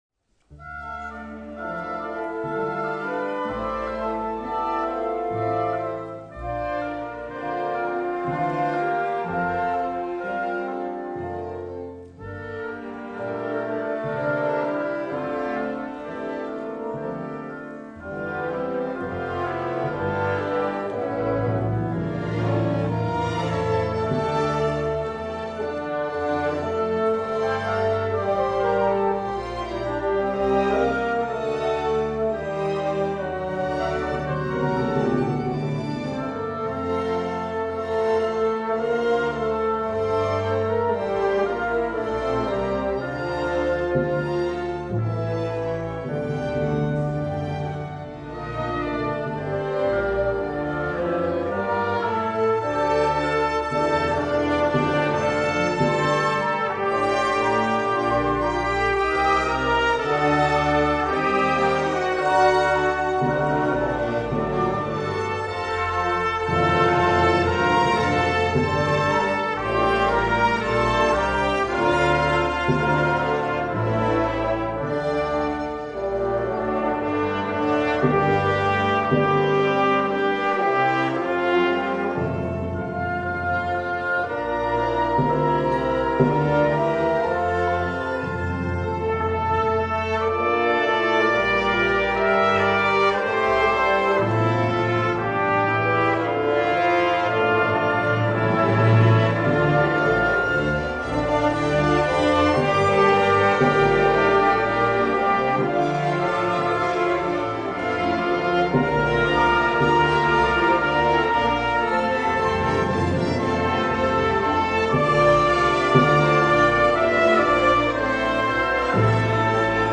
Bearbeitung für großes Orchester